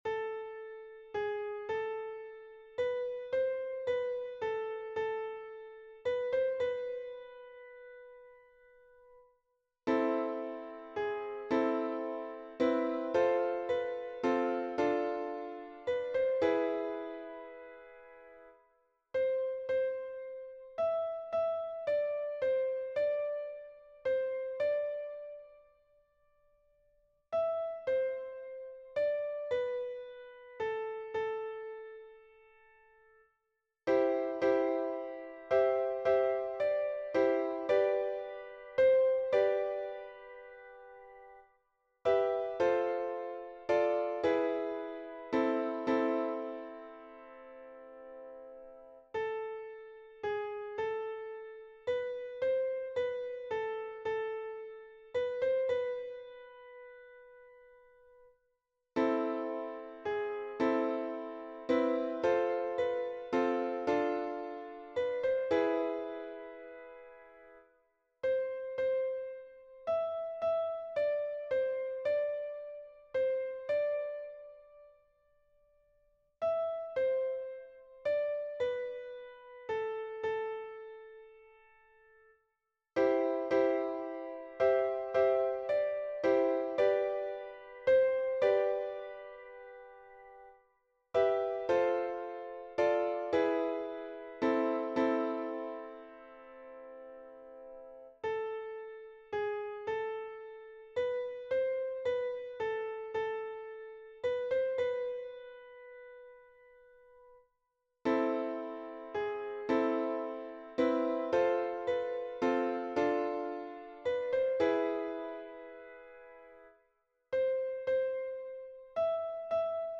- Hymne religieux des corses
MP3 version piano